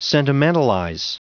Prononciation du mot sentimentalize en anglais (fichier audio)
Prononciation du mot : sentimentalize